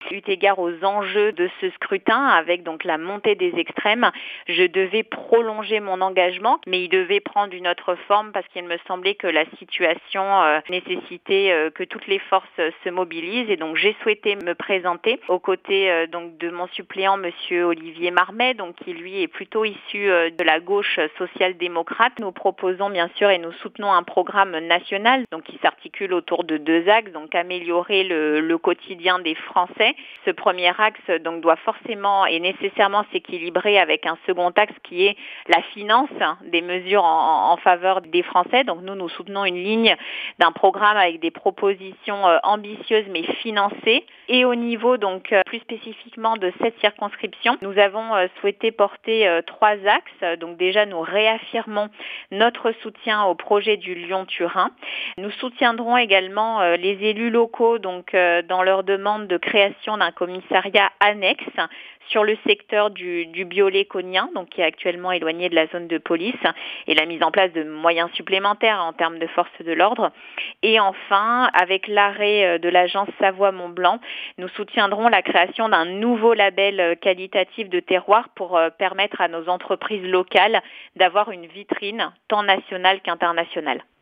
Voici les interviews des 6 candidats de cette 4ème circonscription de Savoie (par ordre du tirage officiel de la Préfecture) :